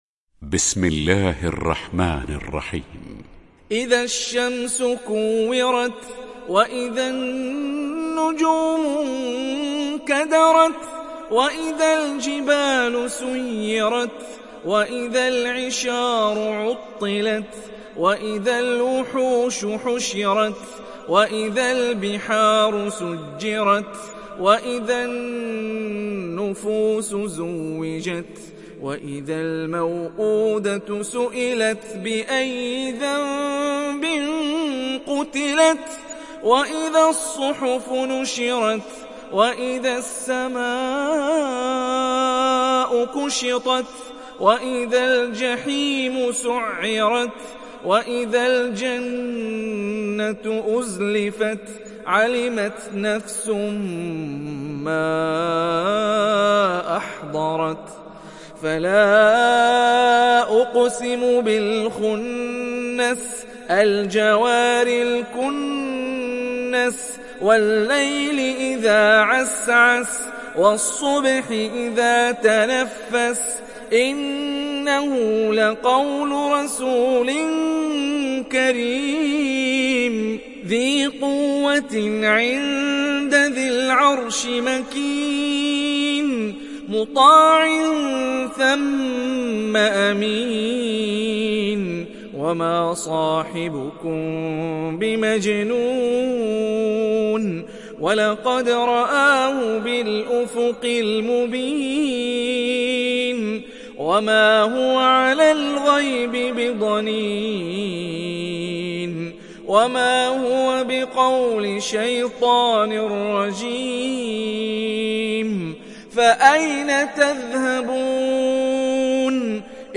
دانلود سوره التكوير mp3 هاني الرفاعي (روایت حفص)